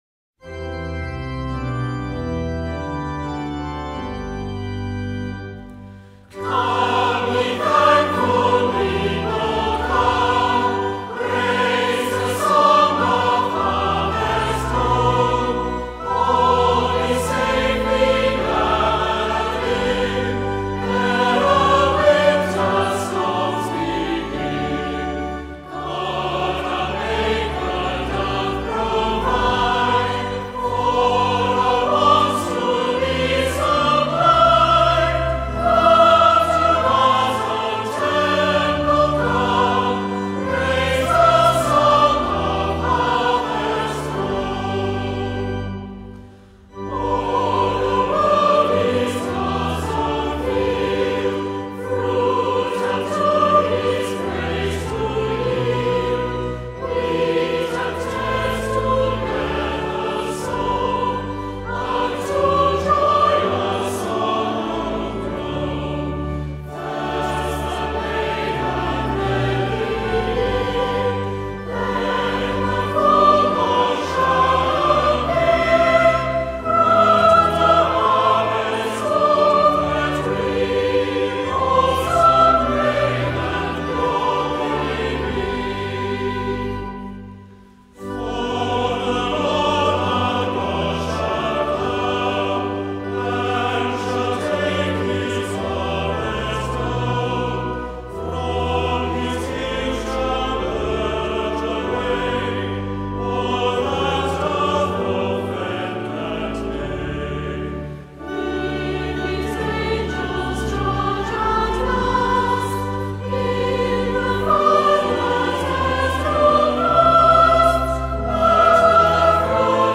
This classic harvest hymn
stately tune